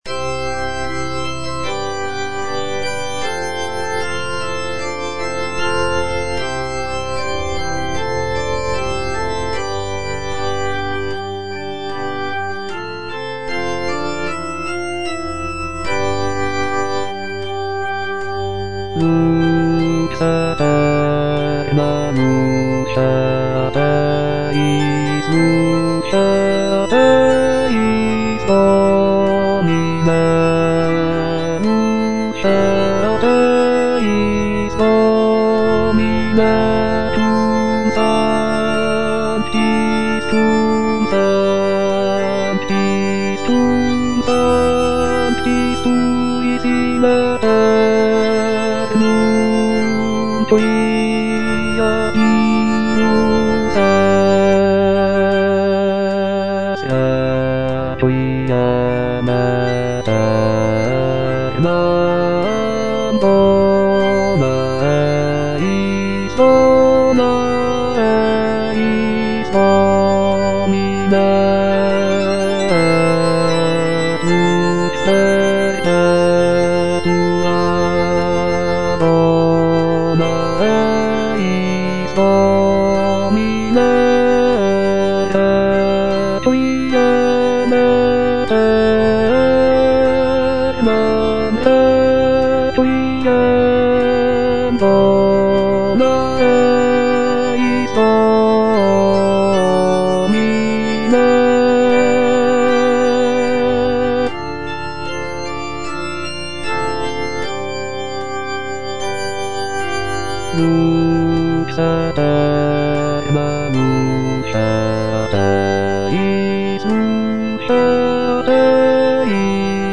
bass I) (Voice with metronome
is a sacred choral work rooted in his Christian faith.